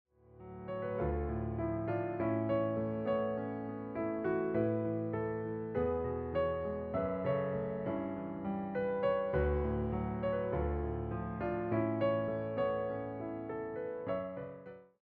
interpreted through solo piano.